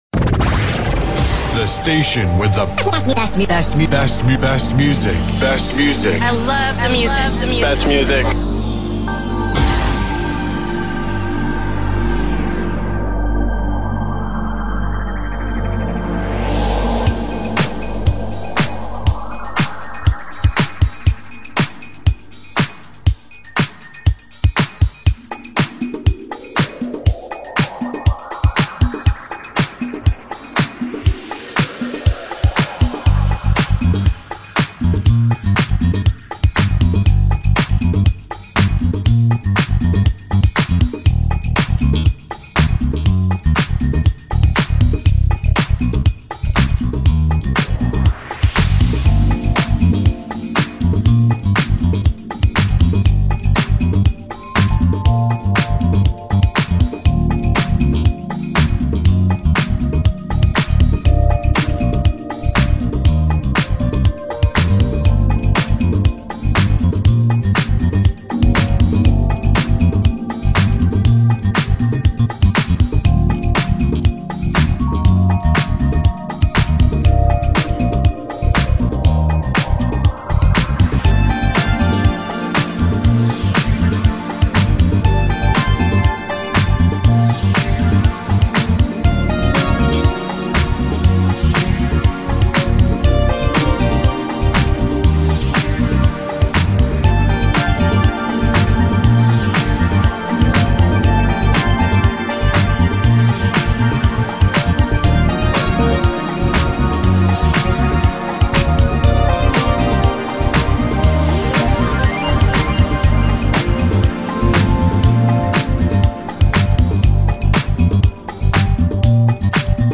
IN THE MIX#NU DISCO#HOUSE# [🏠💻]